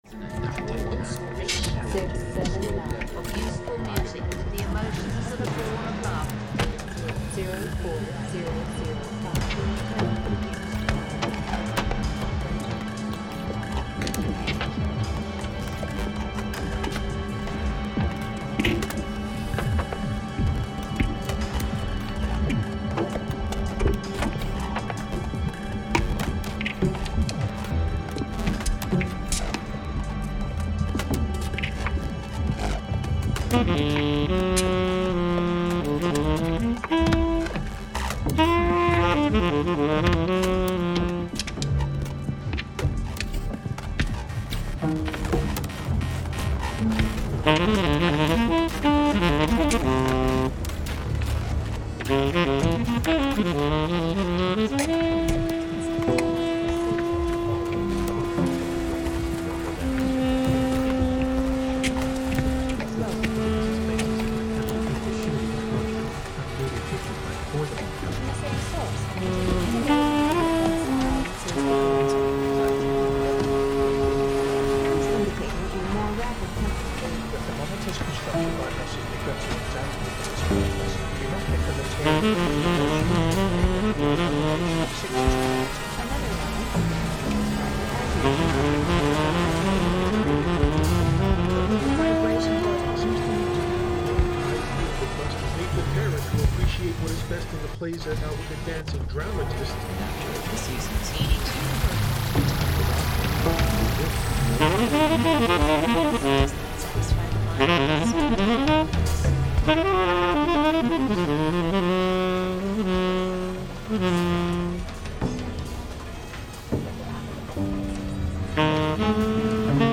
Saxophone
Double Bass
Drums
Live music and video from Melbourne, Australia.
live streamed on 13 June 2025